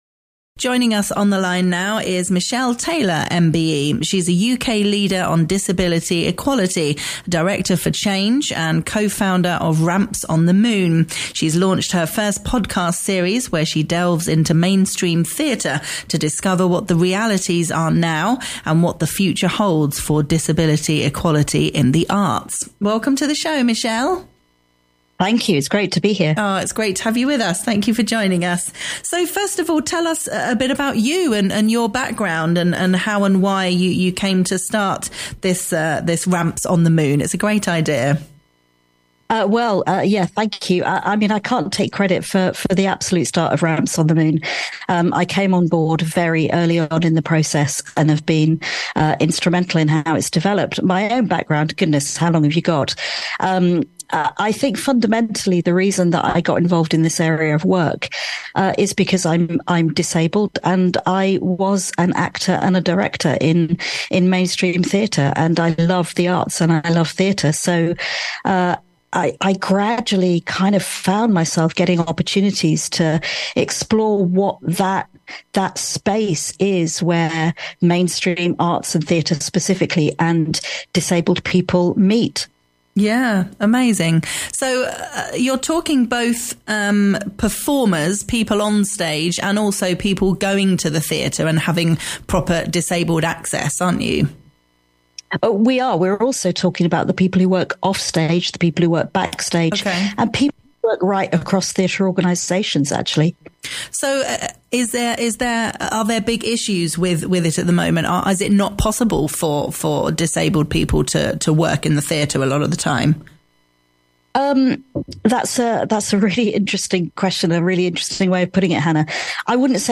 in conversation about the past, current and future realities of disability equality in theatre